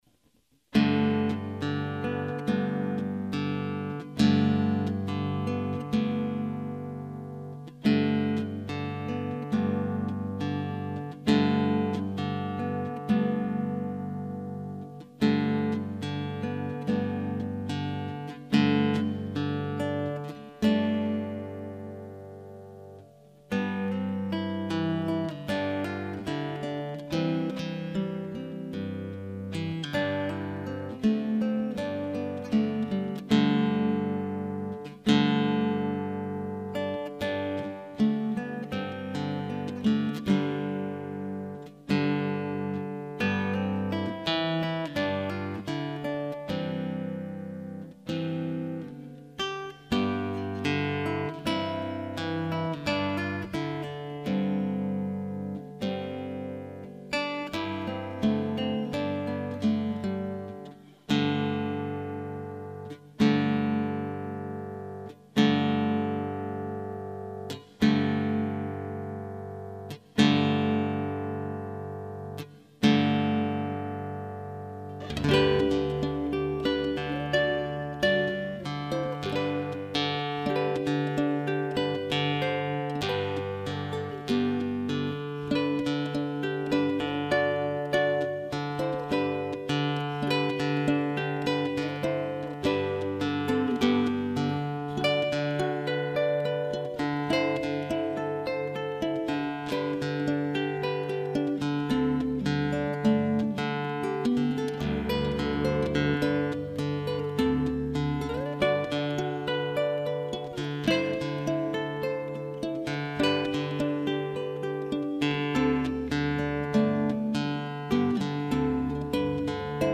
MP3 Study Track